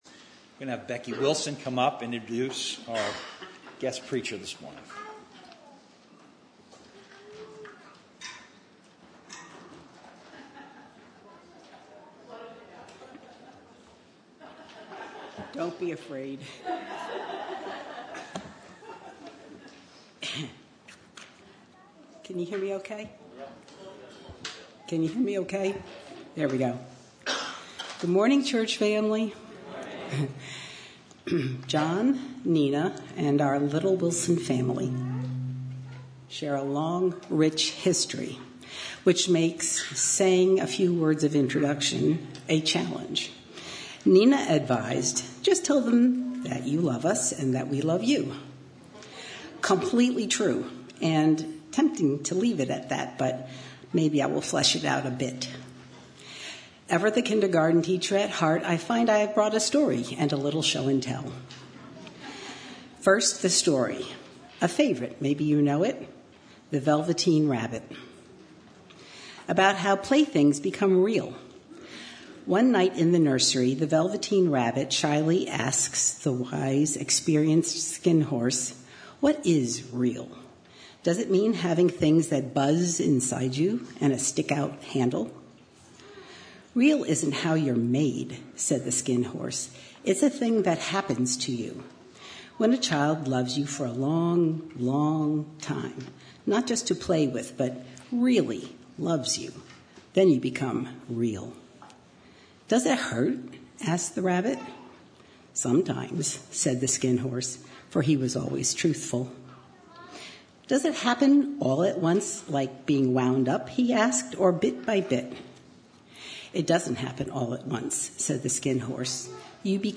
From Series: "50th Anniversary Sermons"